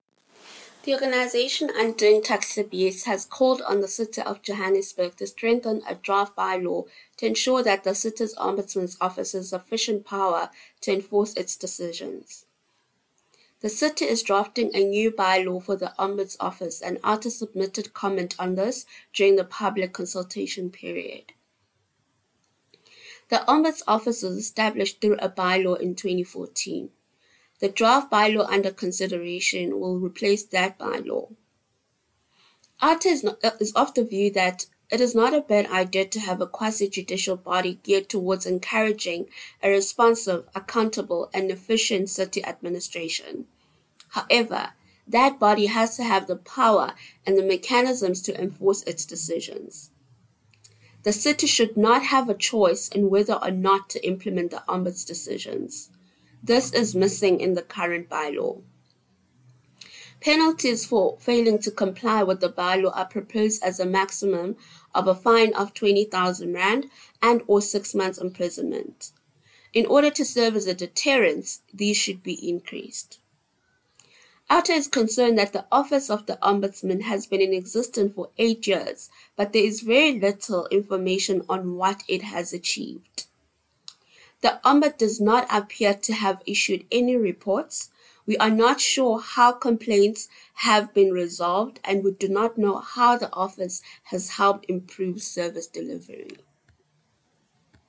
A voicenote with comment